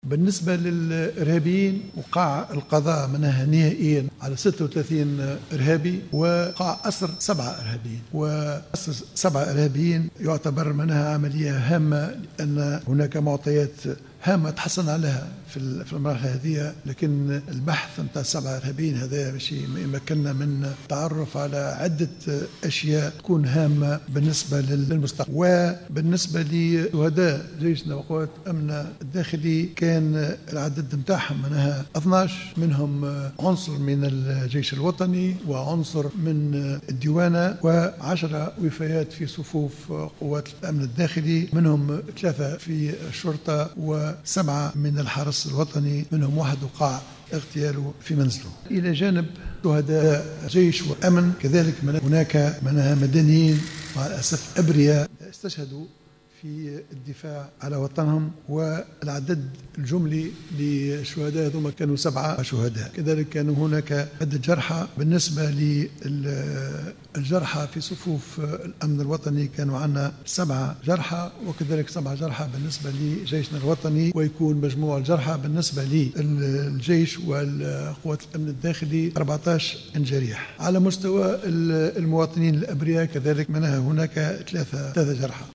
كشف رئيس الحكومة الحبيب الصيد خلال ندوة صحفية عقدها صباح اليوم الثلاثاء 8 مارس 2016 عن الحصيلة النهائية للهجوم الإرهابي والعملية الأمنية التي شهدتها مدينة بن قردان والتي أسفرت عن القضاء عن 36 ارهابيا وأسر 7 ارهابيين اخرين .